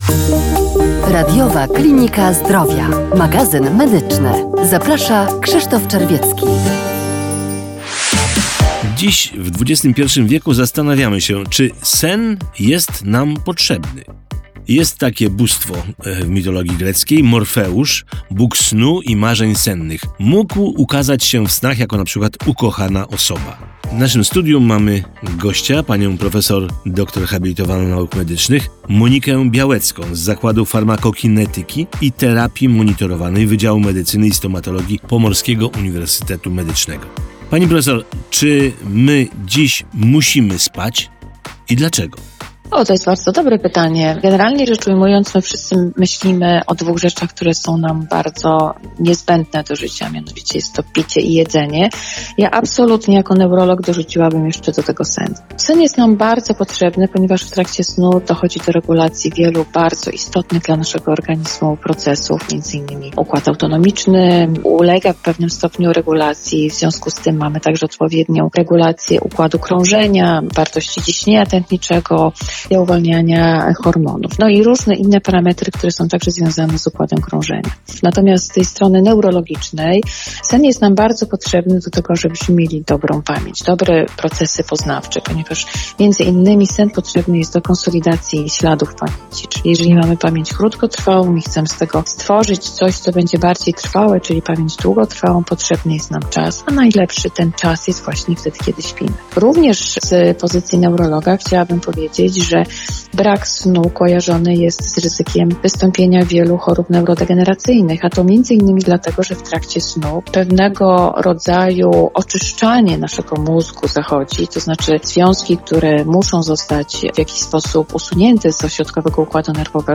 Radio PłockFM